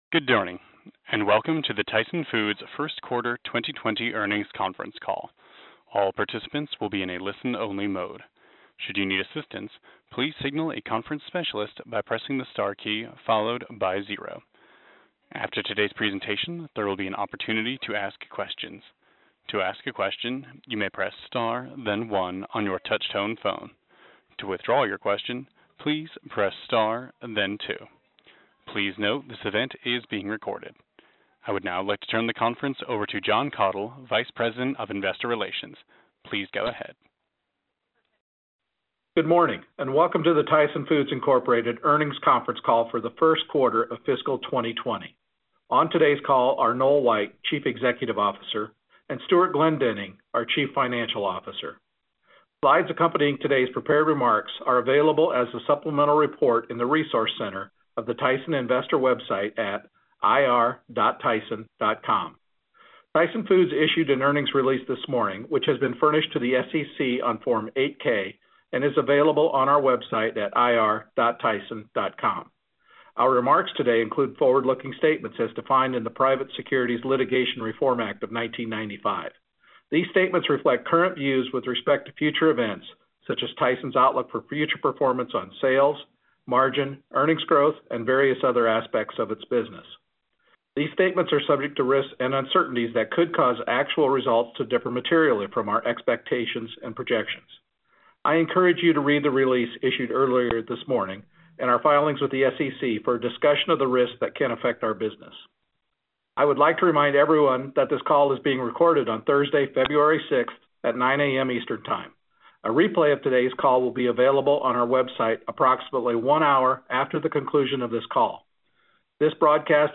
Tyson Foods Inc. - Q1 2020 Tyson Foods Earnings Conference Call